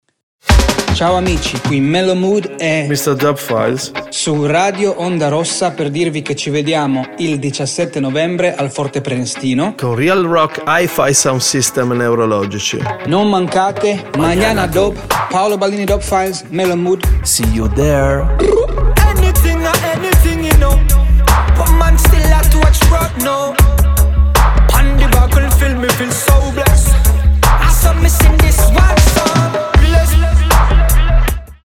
- promo RadioOndaRossa con musica.mp3